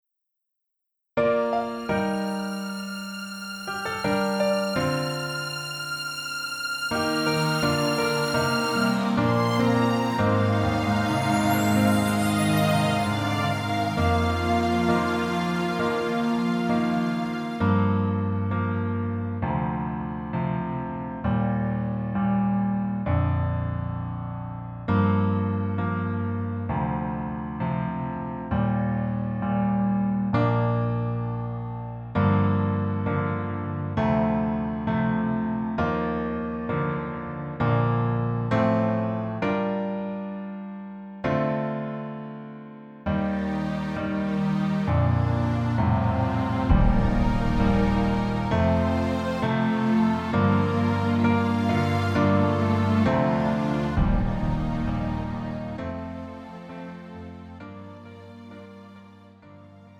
음정 -1키 3:57
장르 가요 구분 Pro MR